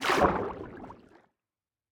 Minecraft Version Minecraft Version latest Latest Release | Latest Snapshot latest / assets / minecraft / sounds / ambient / underwater / enter2.ogg Compare With Compare With Latest Release | Latest Snapshot